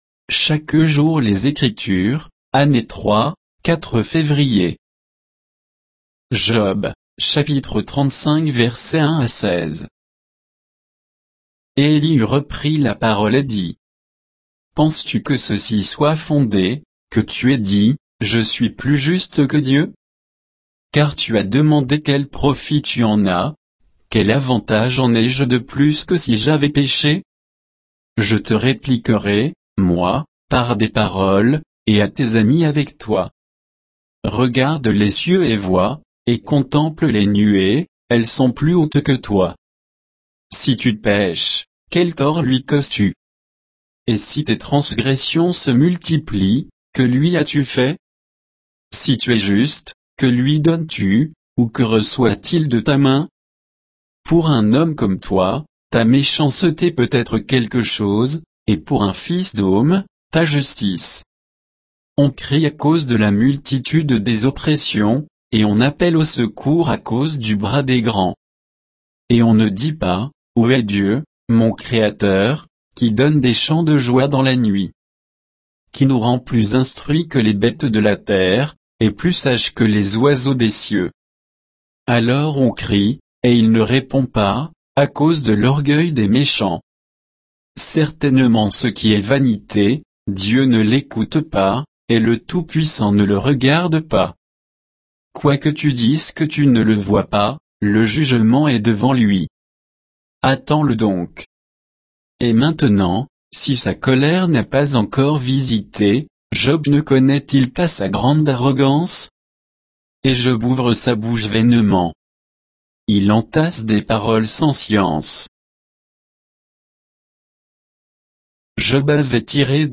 Méditation quoditienne de Chaque jour les Écritures sur Job 35